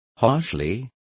Complete with pronunciation of the translation of harshly.